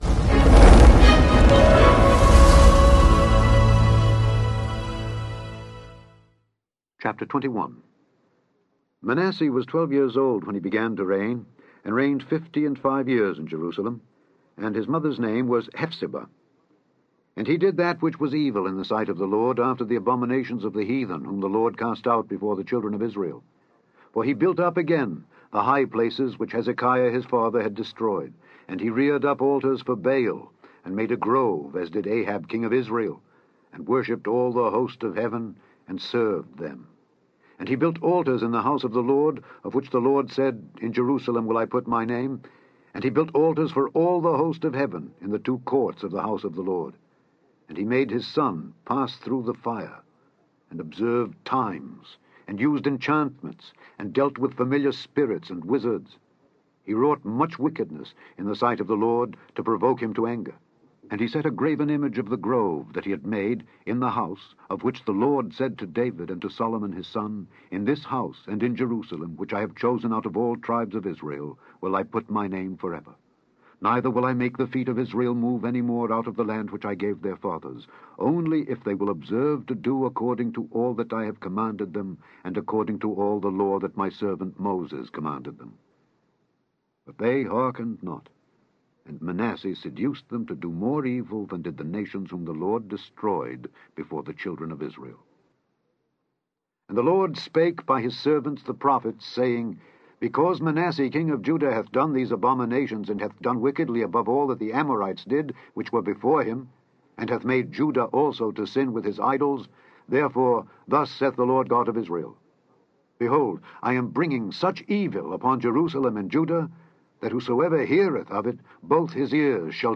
Click on the podcast to hear Alexander Scourby read 2 Kings 21-25.